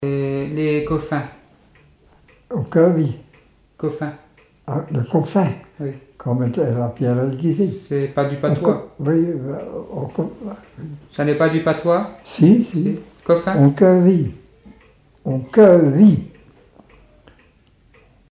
Appels d'animaux, locution vernaculaires, chansons et témoignages
Catégorie Locution